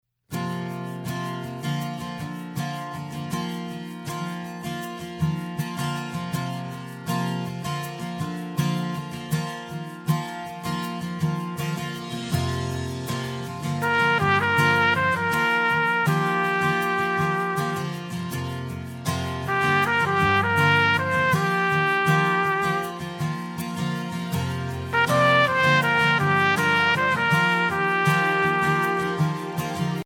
Voicing: Trumpet and Piano